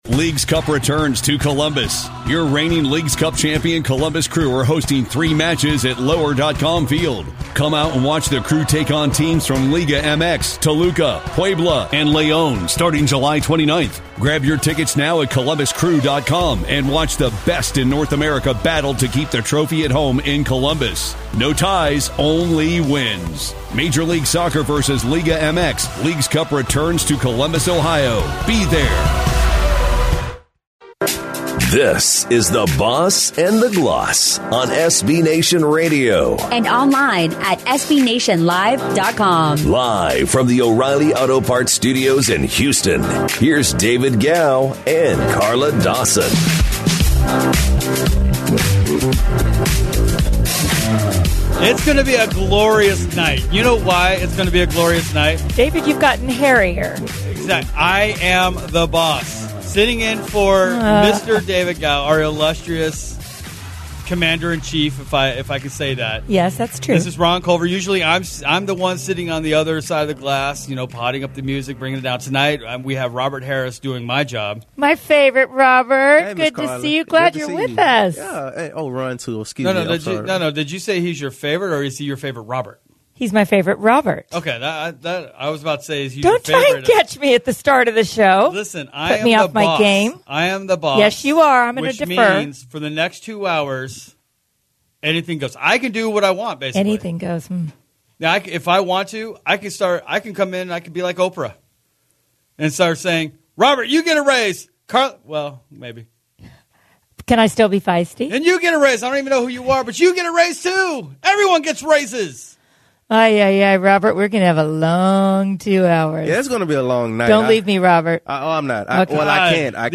March Madness discussion